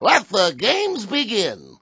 gutterball-3/Gutterball 3/Commentators/Baxter/wack_letgamesbegin.wav at d85c54a4fee968805d299a4c517f7bf9c071d4b9